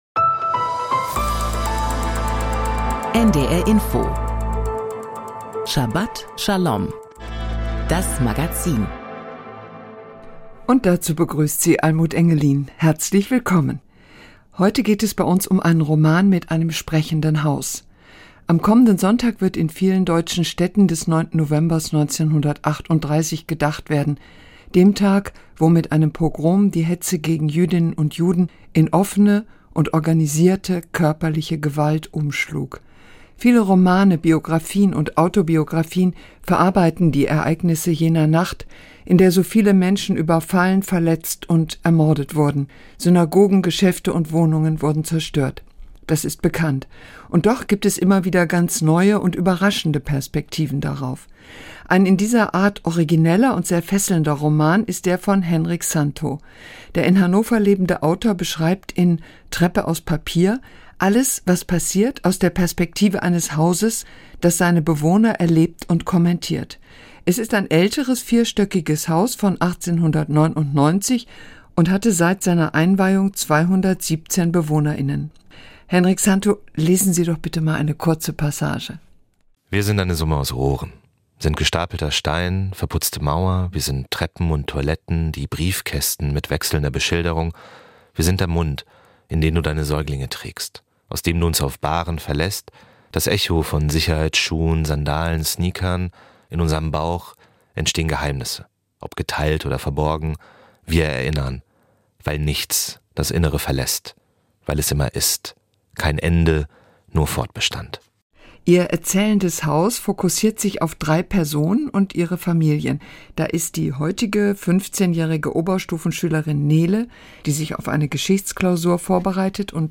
Die Themen der Sendung: Täter- und Opfererfahrungen in einem Haus Interview